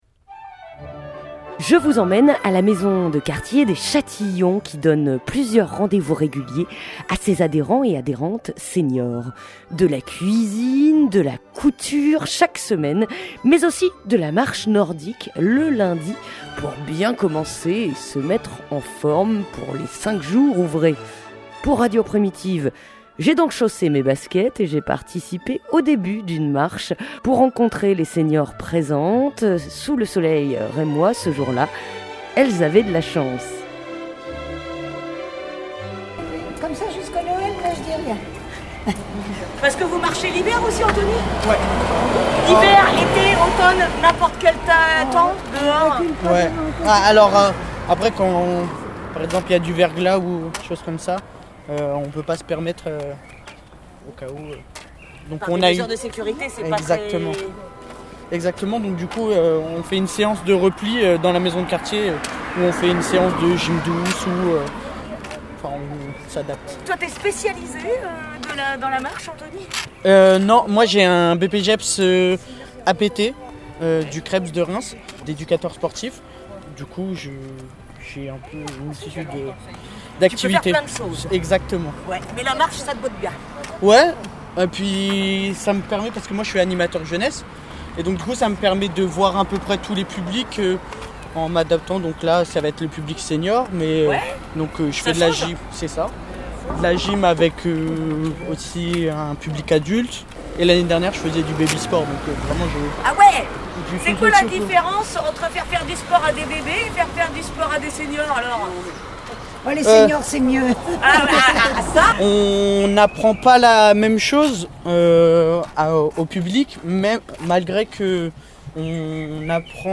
Reportage aux Châtillons (11:57)